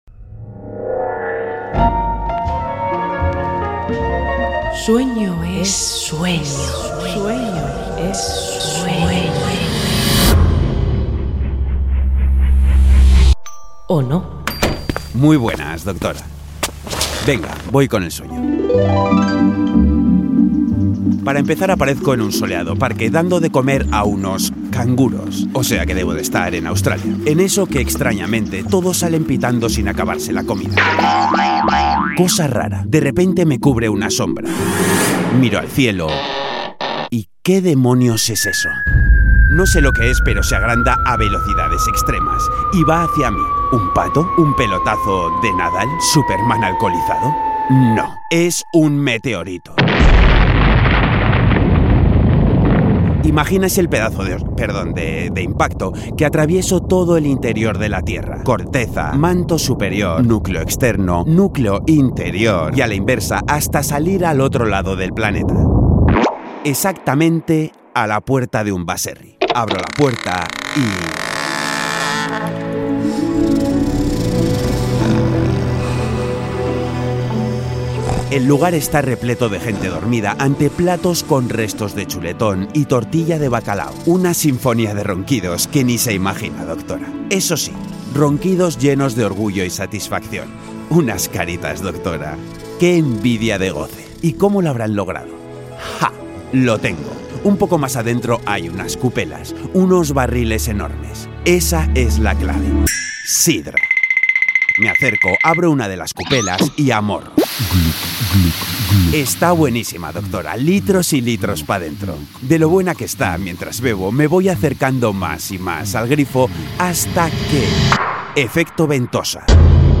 Audio: Suenan tambores…